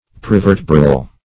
Search Result for " prevertebral" : The Collaborative International Dictionary of English v.0.48: Prevertebral \Pre*ver"te*bral\, a. (Anat.) Situated immediately in front, or on the ventral side, of the vertebral column; prespinal.